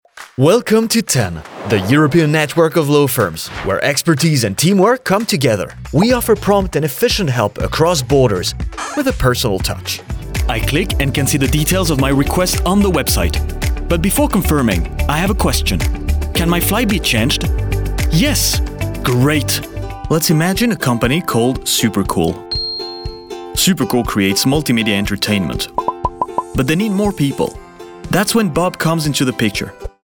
English (International)
Natural, Versatile, Reliable, Friendly, Corporate
Corporate